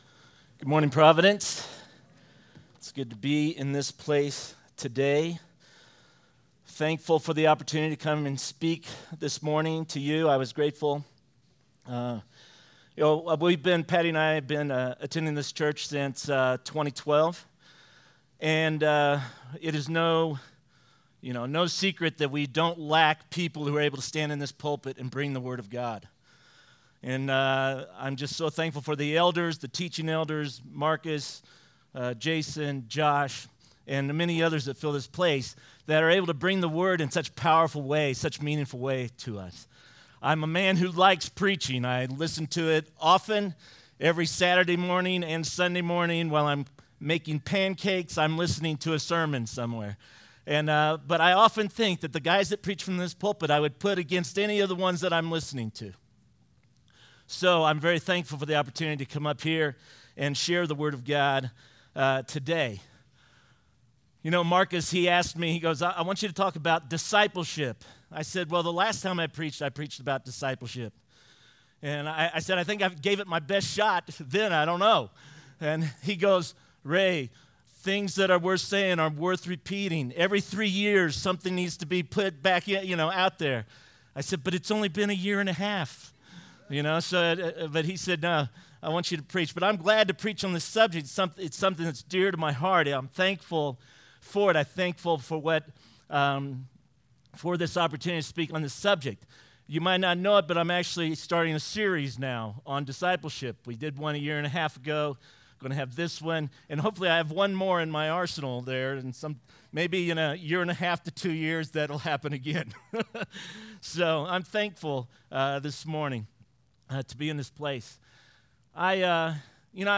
sermon-7.7.19.mp3